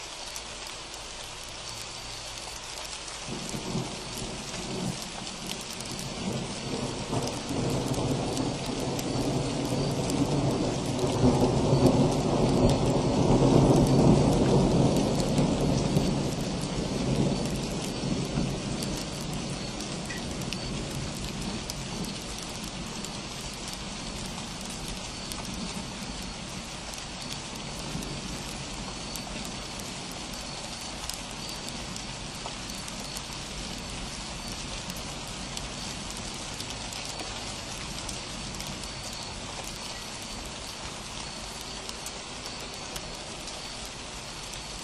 Both mics have their own power source, a single AA battery, and both were feeding into an Olympus VN-8100PC digital audio recorder. Both were also pointed in the same general direction from the same location on the front step, about 30° upwards over the tops of the nearby trees where I could see the flashes and occasional lightning bolt, and by this time it was raining steadily, as can be heard.
Parabolic dish mic test
I had amplified this one slightly to get the ambient rain sound in the same general realm for both recordings, so the thunder could be more directly compared, and I let the recording run until I could no longer make out the echoes of the thunder.
That’s a world of difference – the parabolic dish really doesn’t capture the lower registers well at all, though I think the shotgun mic is both optimized for them and has more of a built-in pre-amplifier.
ParabolicThunder.mp3